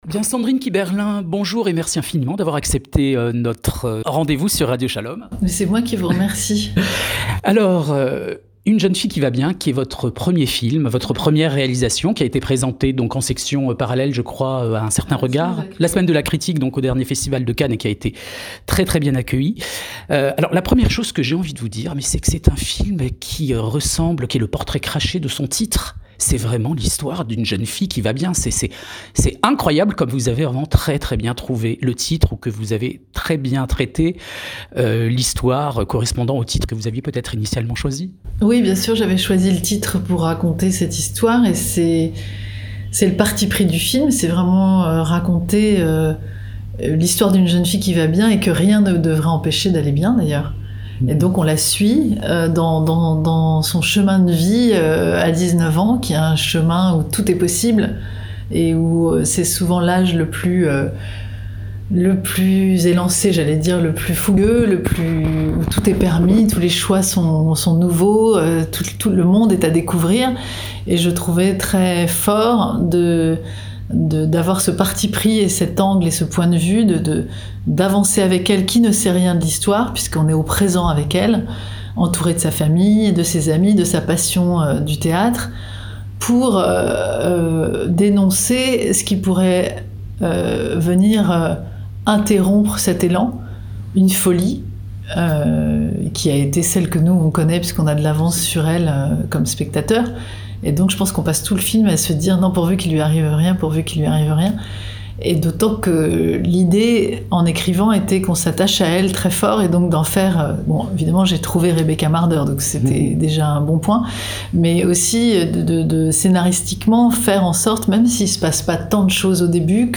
Sandrine Kiberlain derrière la caméra pour traiter de l’antisémitisme qui la « hante ». Interview sur Radio Shalom
Plaisir du partage et de l'échange avec la formidable Sandrine Kiberlain qui a accordé une interview à Radio Shalom à l'occasion de son premier film en tant que réalisatrice : UNE JEUNE FILLE QUI VA BIEN.